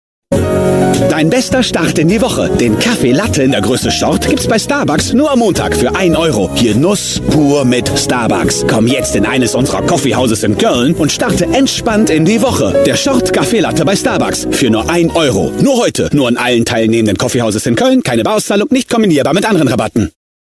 Jung - dynamisch - voll - warm - variable
Sprechprobe: Werbung (Muttersprache):
dynamic - full - warm